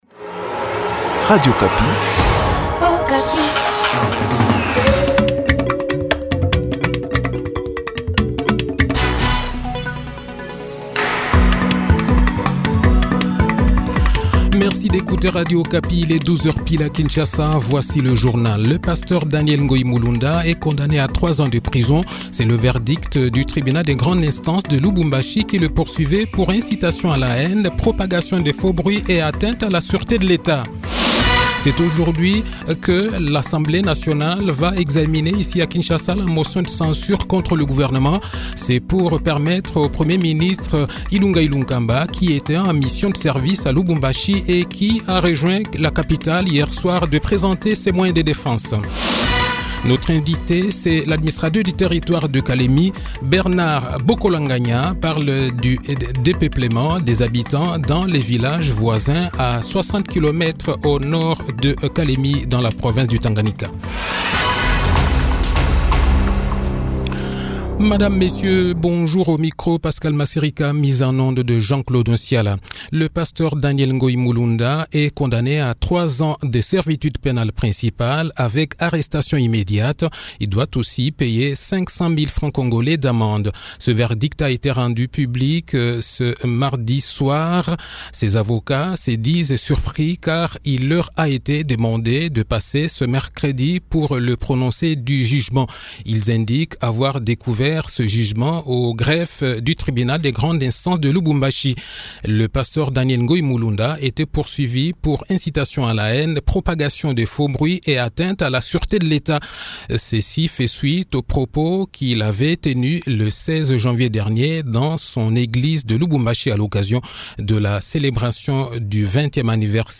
Le journal-Français-Midi